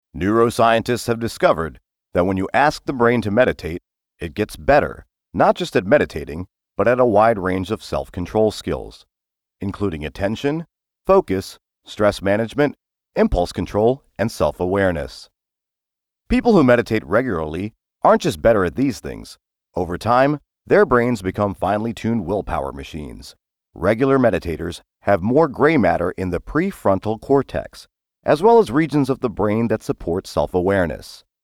Factual Reads
Accent: American